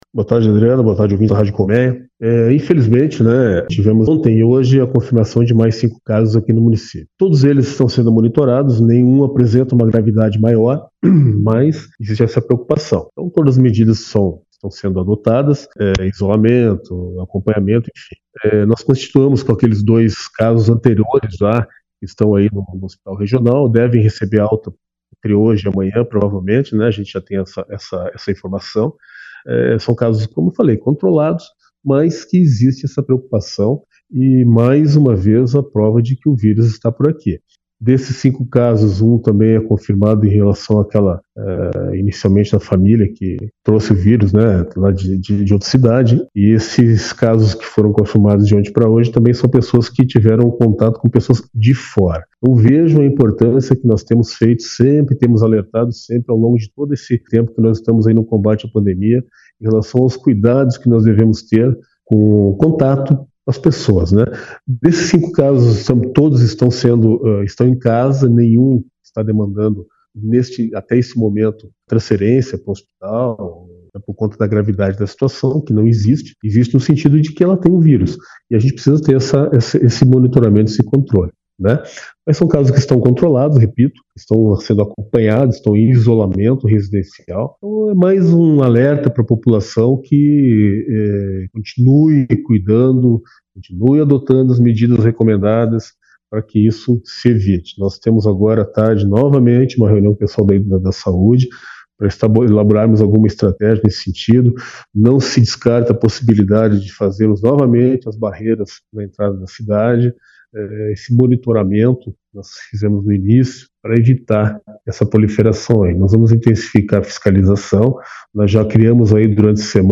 O prefeito do município, Luís Otavio Saraiva, ressaltou em entrevista ao Jornal Colmeia, que todos os casos estão em isolamento domiciliar, sendo monitorados pela equipe médica da secretaria de Saúde.